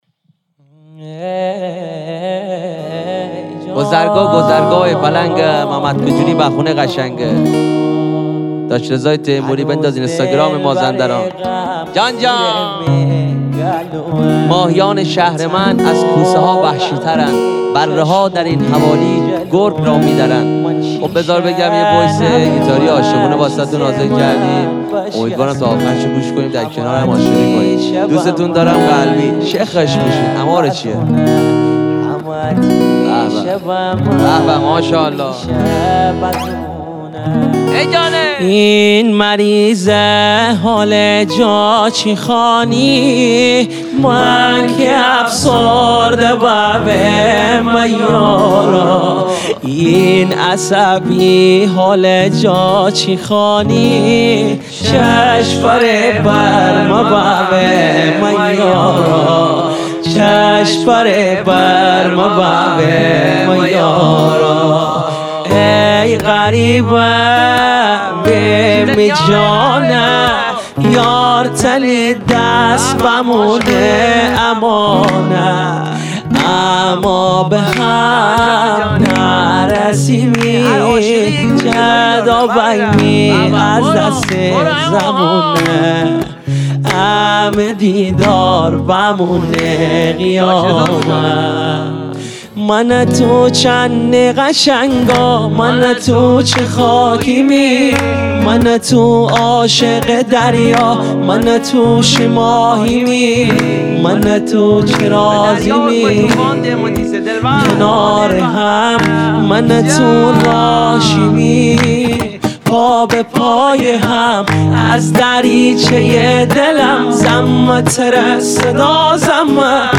وویس گیتاری عاشقونه